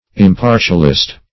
Impartialist \Im*par"tial*ist\, n. One who is impartial.